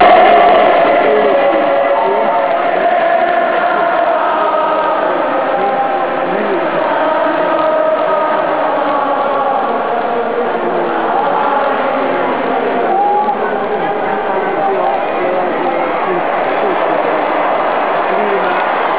Ancora brividi, coro da 70 mila persone..